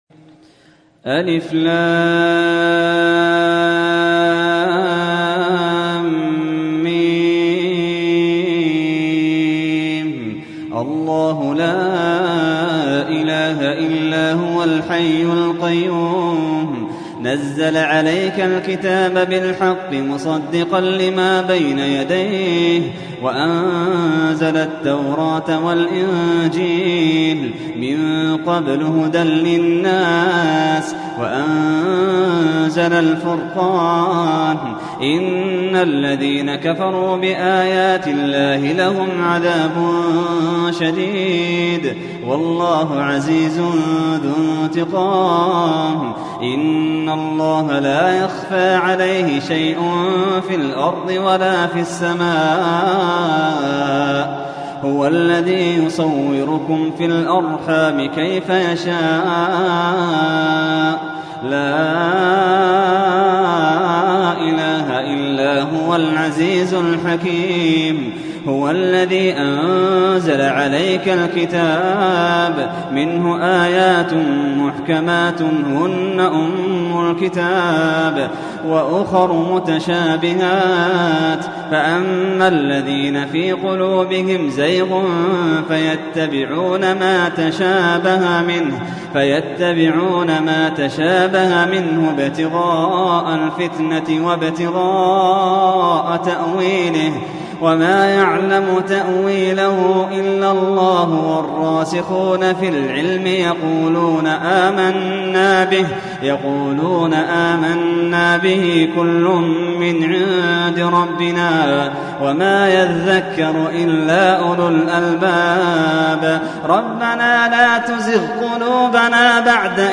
تحميل : 3. سورة آل عمران / القارئ محمد اللحيدان / القرآن الكريم / موقع يا حسين